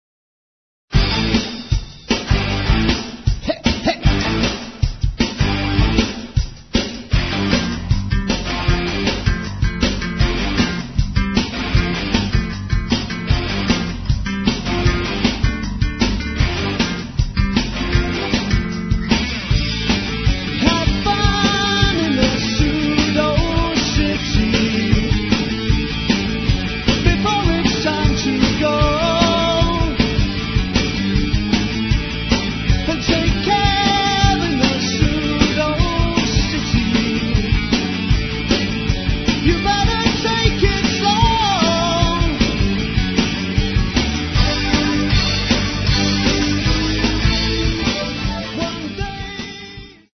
Farm Studio, Rainham, Essex.